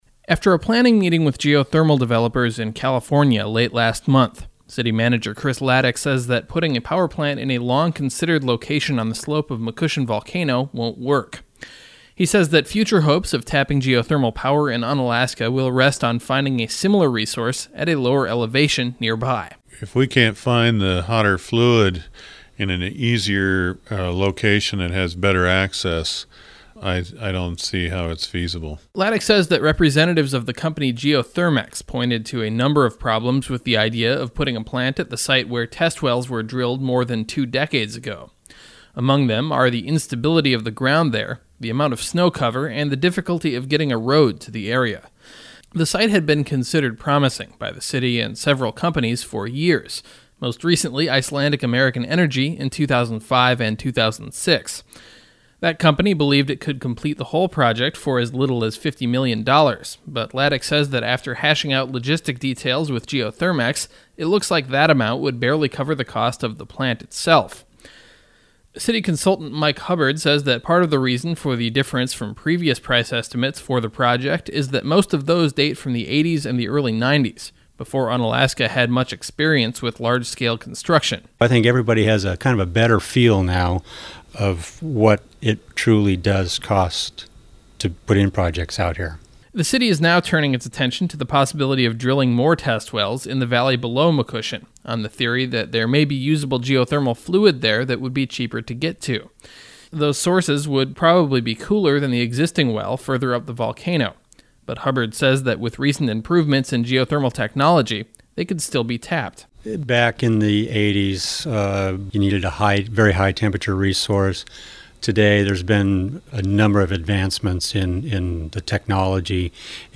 By KIAL News